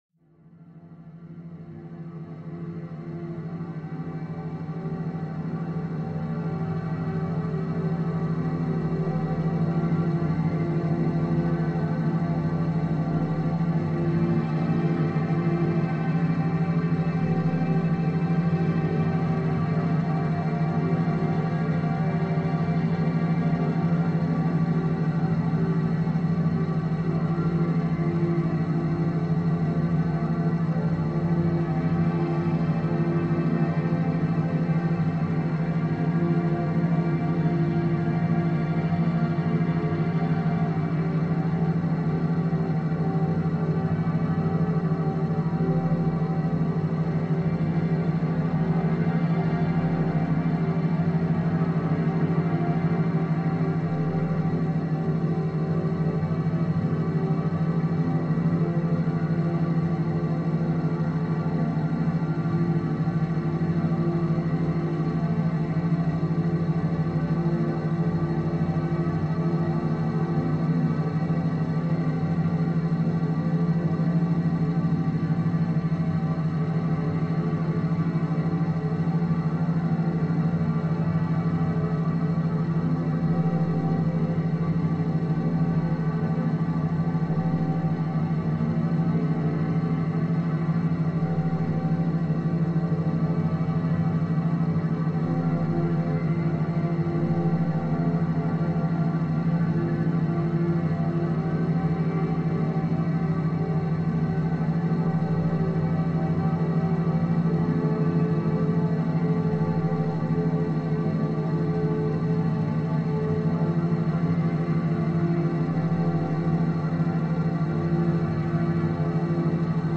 Pluie douce en 528 Hz · méthode scientifique 2 heures productives